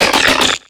Cri de Mysdibule dans Pokémon X et Y.